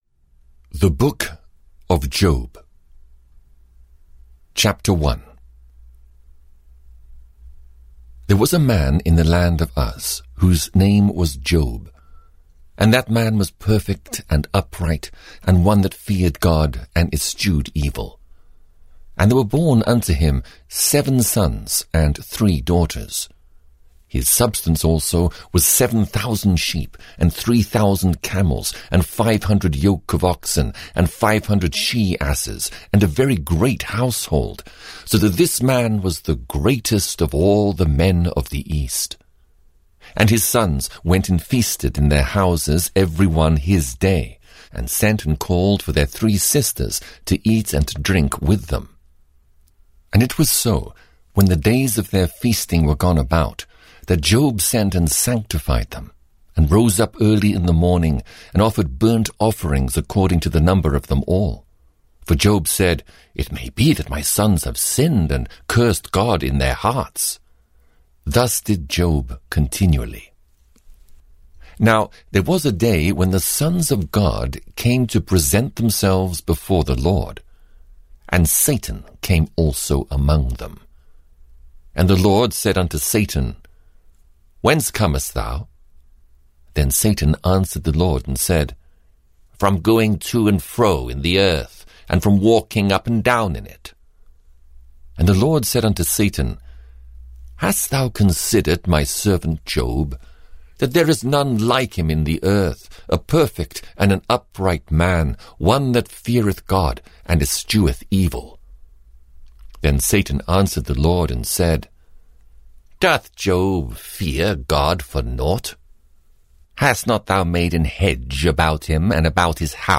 The Old Testament 18 - Job (EN) audiokniha
Ukázka z knihy